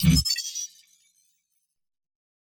Deep HiTech UI Sound 3.wav